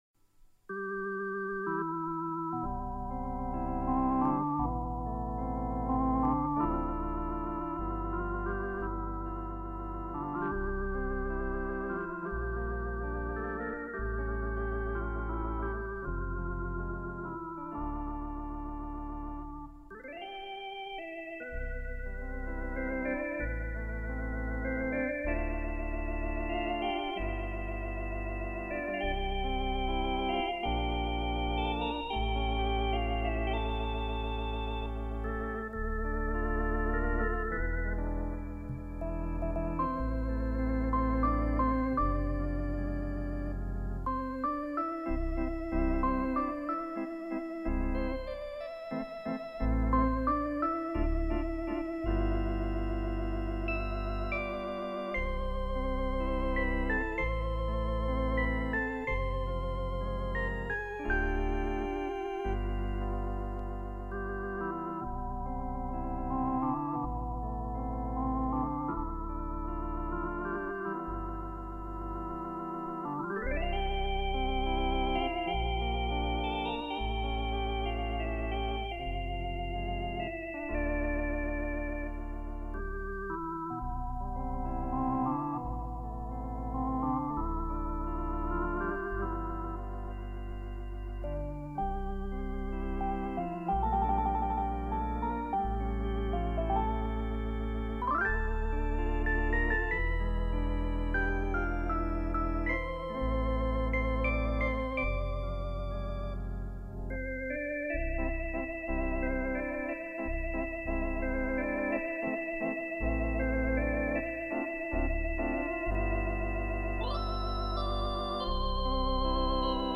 Post tonewheel – Concorde
Ebb Tide — Once upon a Time in the West (x2) — Raindrops keep falling on my head — Mozart: petite musique de nuit. Practice tunes from a slow learner, 1980.